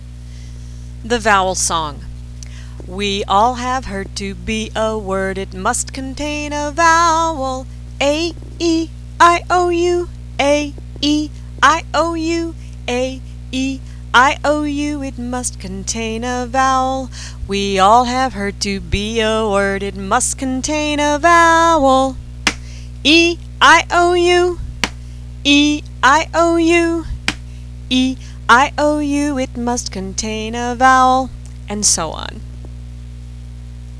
The Vowel Song (Sung to the tune of B-I-N-G-O)
Just like the familiar BINGO song, you drop a letter each time through and insert a CLAP. By the end of the song, you're clapping for all the missing vowels.